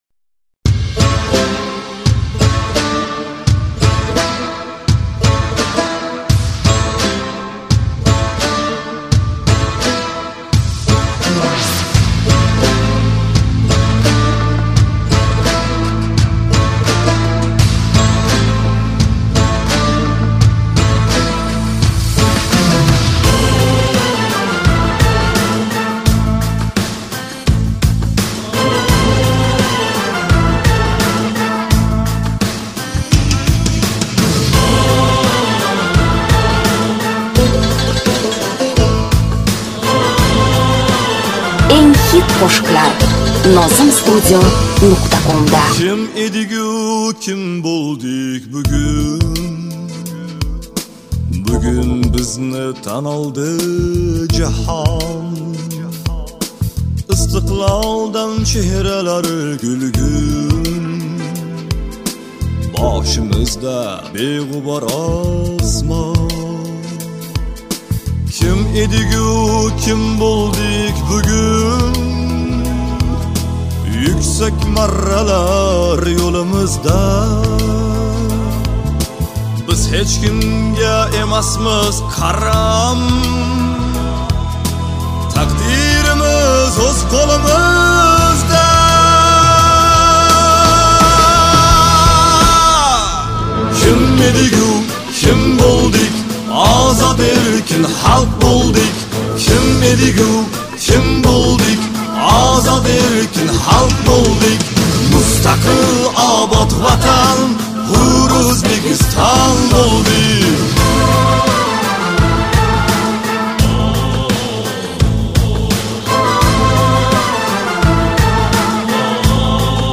minus